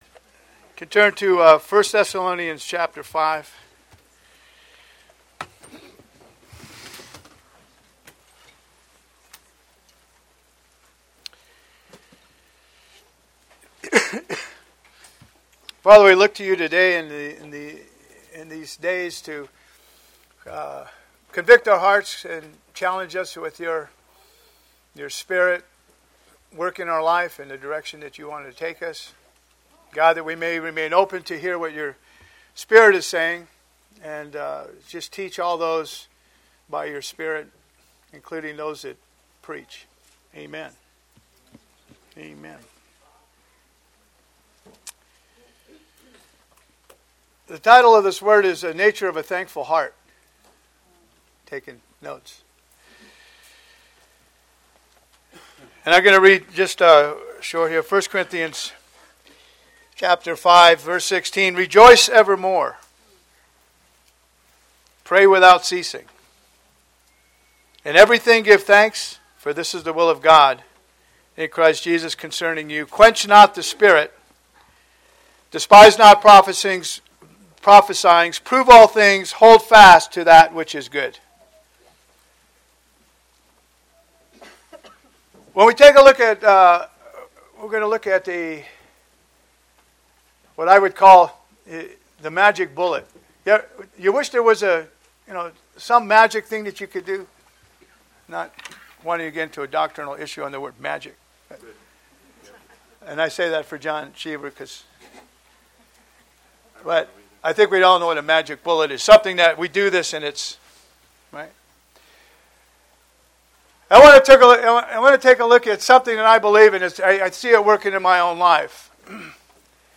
Posted in 2017 Shepherds Christian Centre Convention